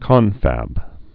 (kŏnfăb) Informal